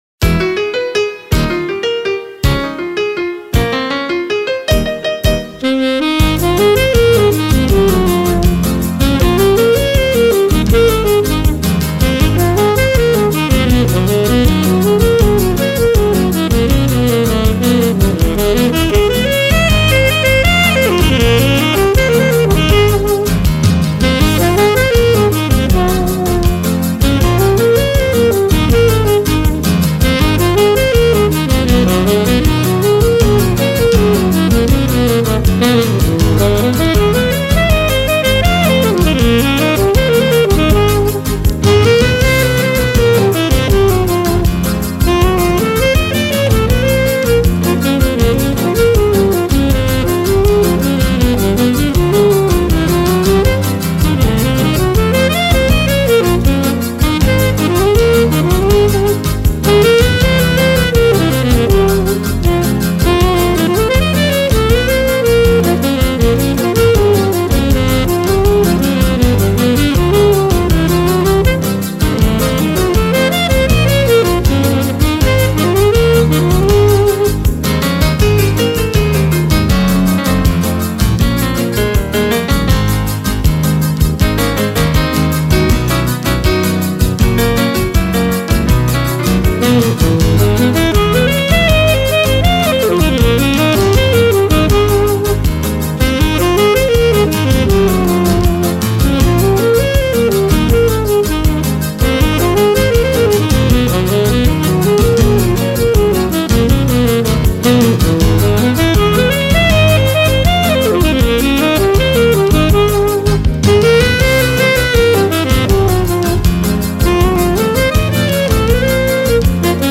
03:22:00   Choro(Chorinho)